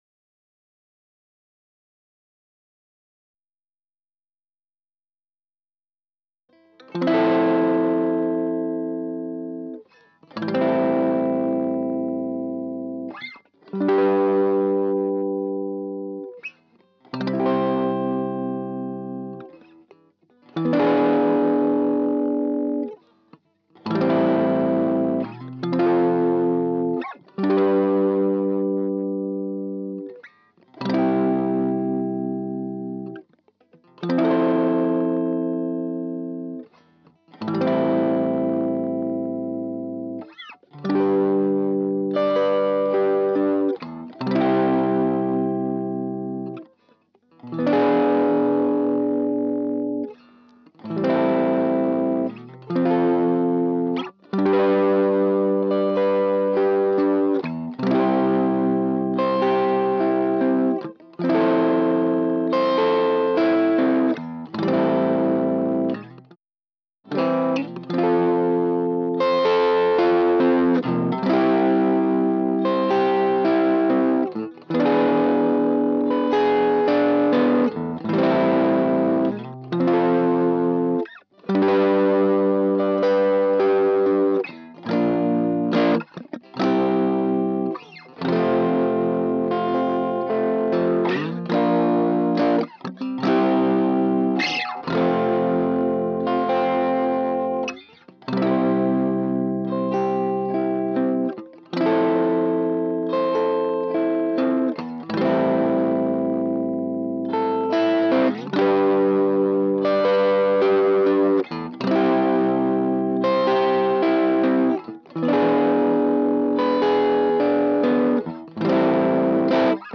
electricguitar.wav